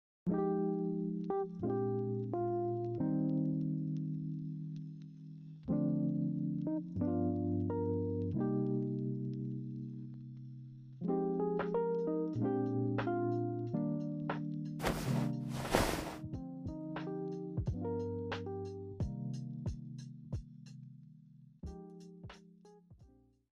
帰さなくていい？【シチュボ 1人声劇】